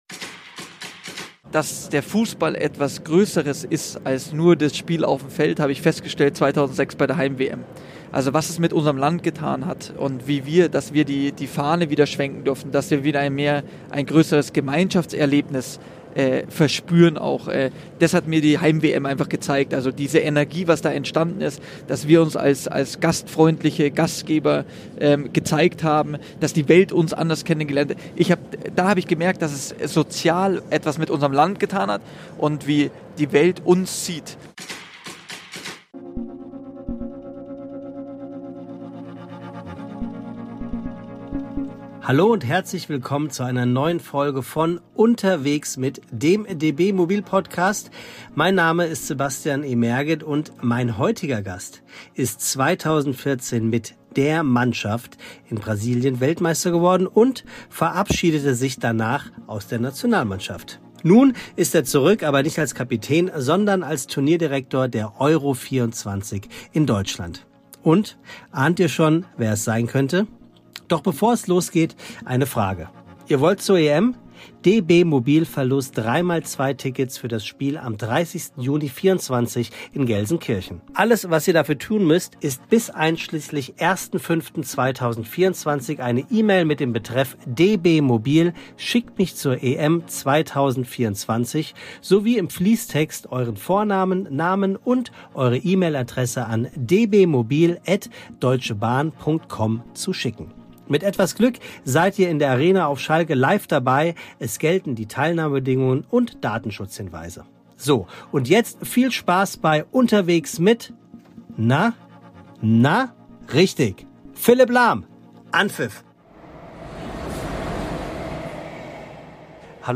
Ein spannendes, emotionales Gespräch über Lahms Top-Favoriten für den EM-Sieg, sein Engagement im Fußballverein seines Sohnes und darüber, warum er nur zwei Mahlzeiten am Tag zu sich nimmt.